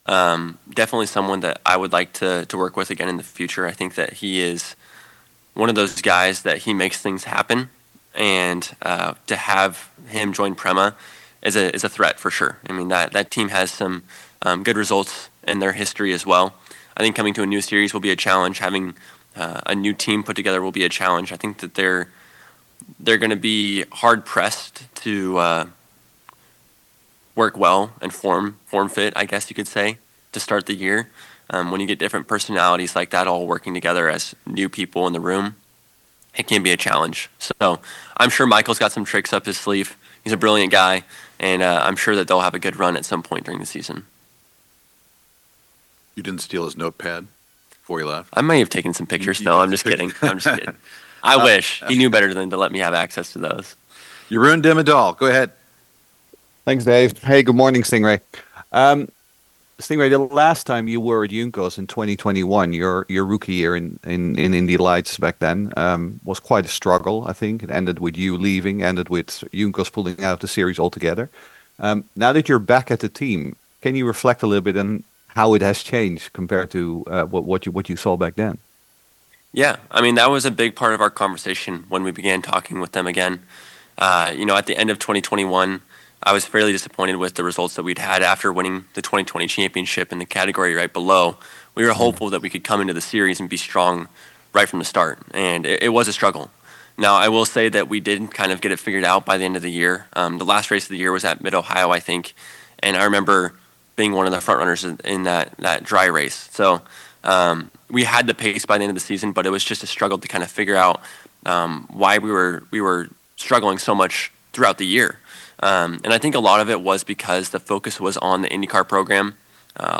Sting Ray Robb IndyCar driver Talks new team and 2025 Outlook January 2025